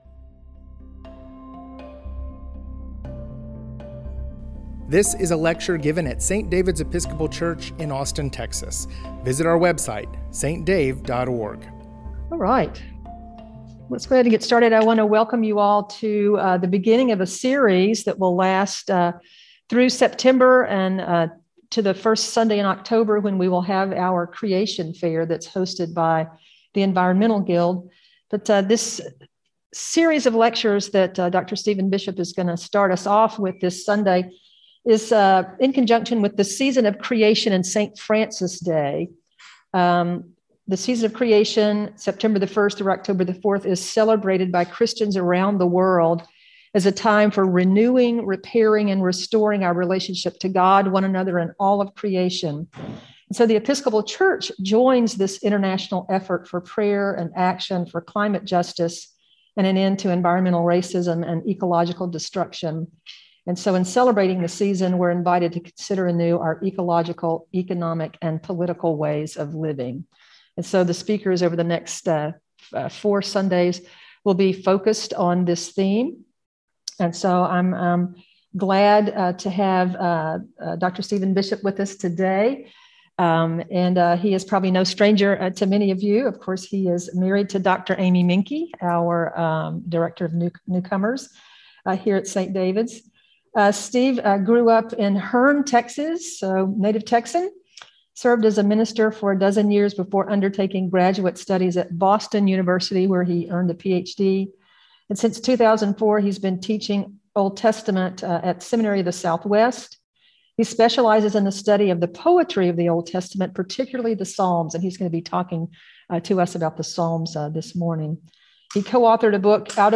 Season of Creation Lecture Series: Creation Through the Eyes of the Poet: The Psalms and Creation